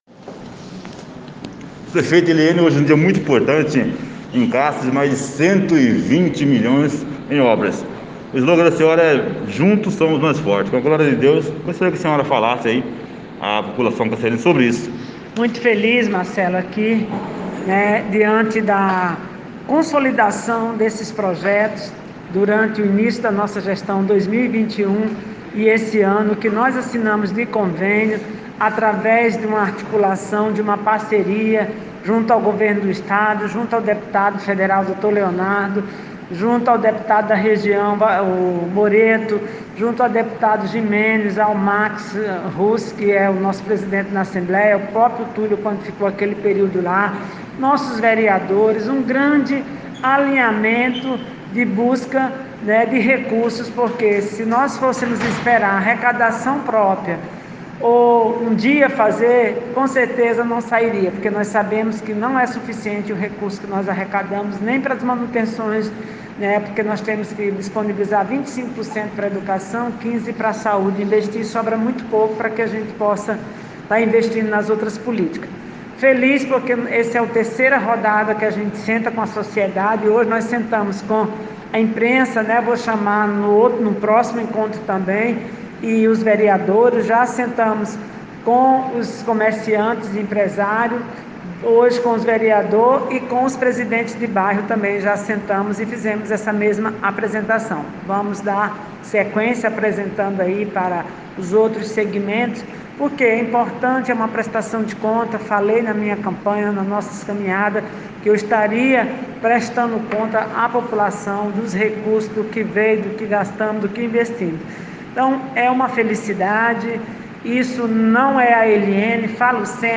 A prefeita de Cáceres Eliene Liberato deu uma entrevista após uma reunião na tarde de hoje (09).